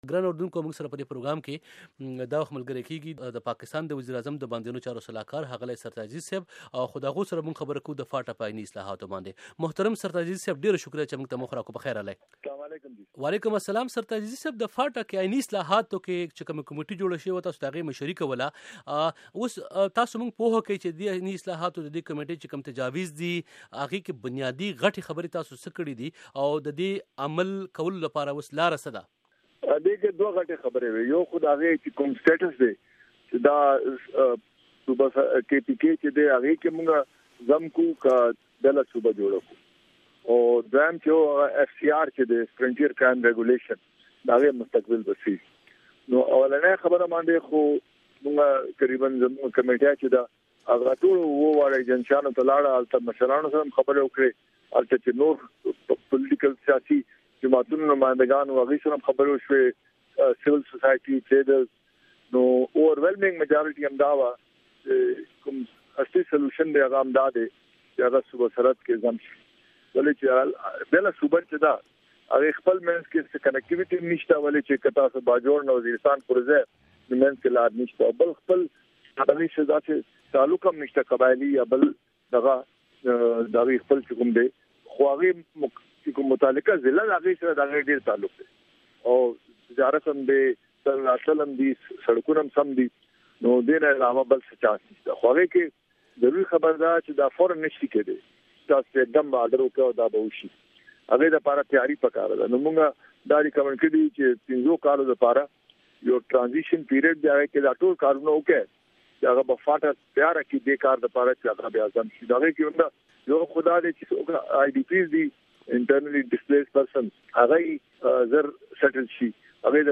د پاکستان د وزيراعظم د باندينو چارو صلاح کار سرتاج عزيز ويلي دي د فاټا د خلقو خواهش دی چې فاټا دې د پښتون خوا صوبې سره یو شي- هغه د ډیوه په خپرونه واشنگټن ټو خېبر کې اوویل چې هغه د فاټا د اووه ایجنسیو په سفر کې د ټولو خلقو د اکثریت دغه خواهش واوریدلو چې فاټا دې د پښتون خوا صوبې برخه شي- هغه اوویل په هر ځاېی کې به ورته خلقو شعارونه کول چې گو ایف سي آر گو-